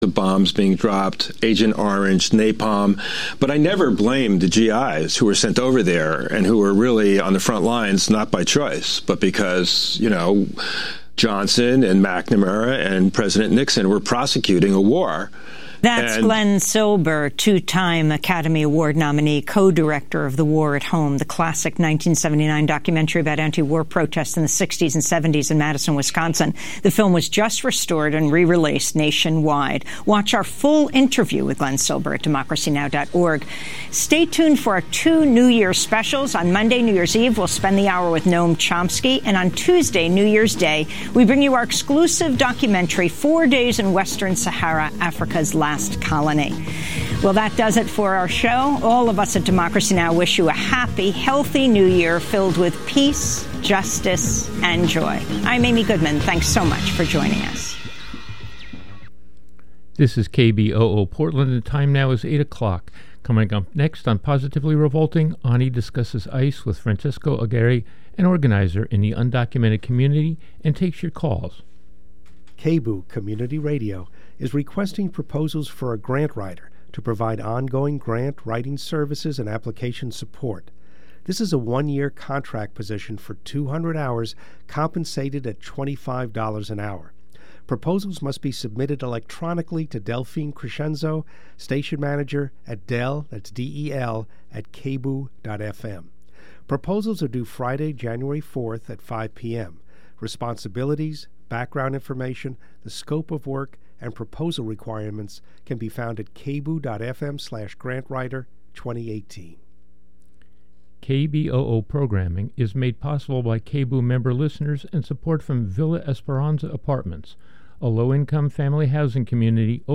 Download audio file Please join me for a conversation with Dahr Jamail, as we talk about the reality of climate change. We'll talk about the rapidity of changes, and how climate models and predictions have consistently fallen short of what is actually happening.